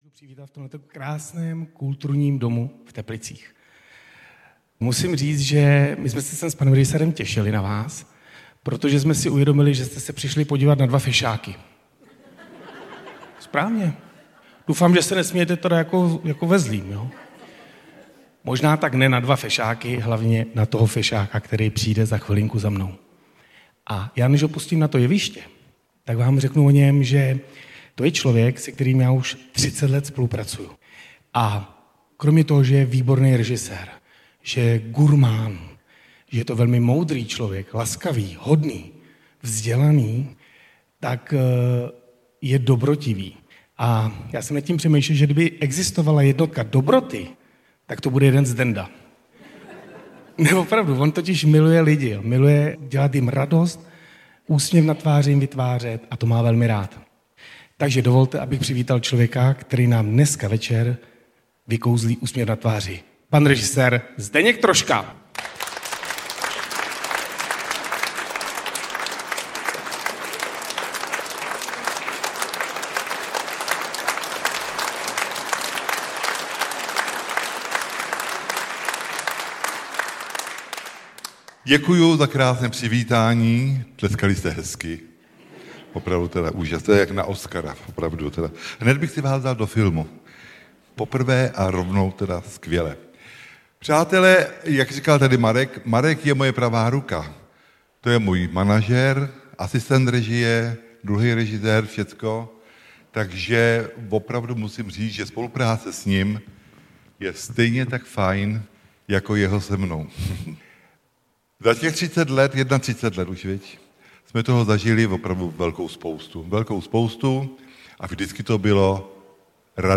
Populární režisér Zdeněk Troška je vtipným vypravěčem historek z natáčení i ze života. Již řadu let baví diváky a posluchače po celé republice veselými příběhy a vzpomínkami, které zažil při natáčení svých úspěšných filmů, na cestách po světě, ale i v rodných Hošticích.
• InterpretZdeněk Troška